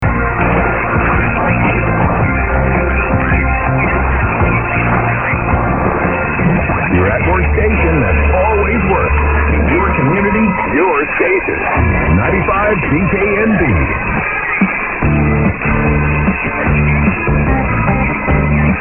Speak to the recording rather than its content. After some attempts at recording at dawn, and hearing virtually nothing, despite the beverage antennas, signals are again starting to re-appear as if by magic.